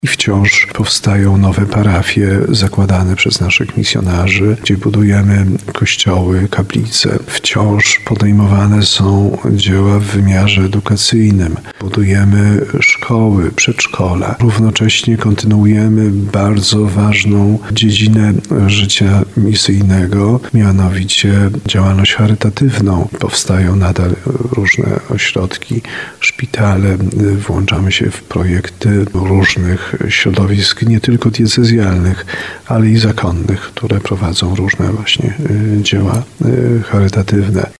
Jak mówi biskup tarnowski Andrzej Jeż, misjonarze głoszę Ewangelię w krajach misyjnych i prowadzą także wiele projektów edukacyjnych i medycznych.